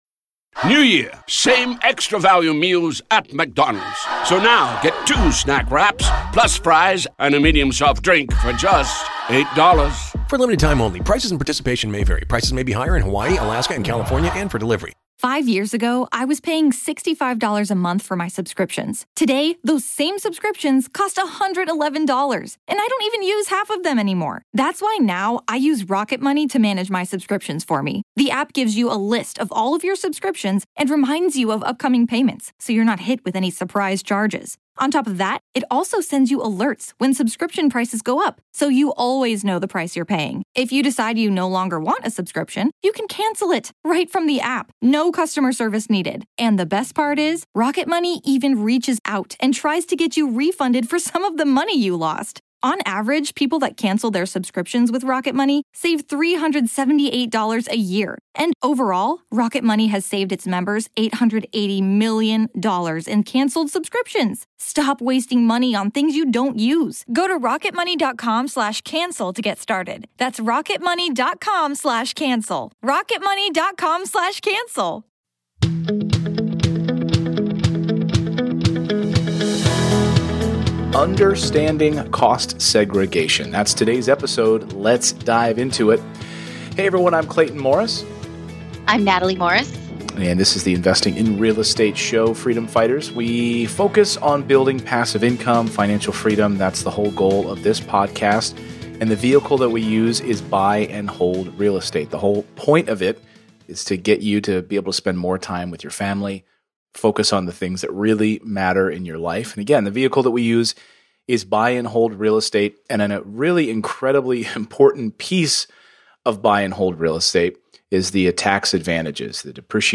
EP341: Understanding Cost Segregations - Interview